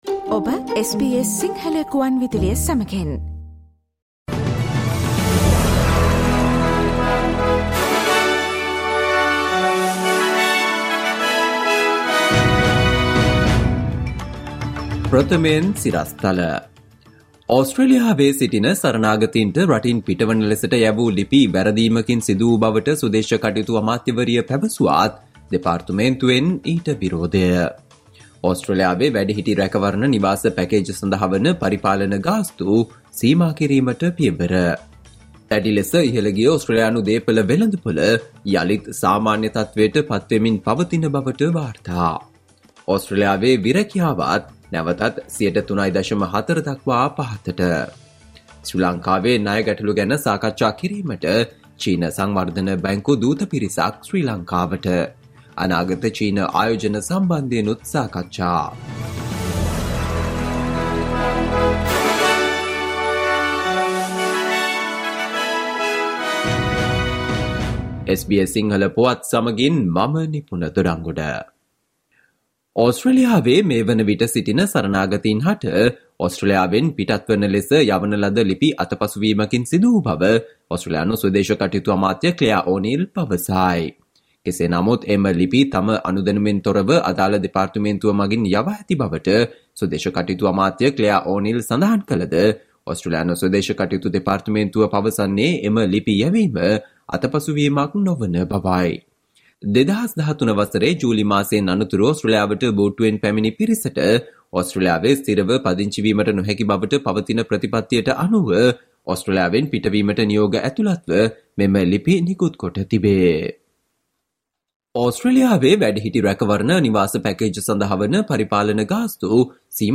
Listen to the SBS Sinhala Radio news bulletin on Friday 18 November 2022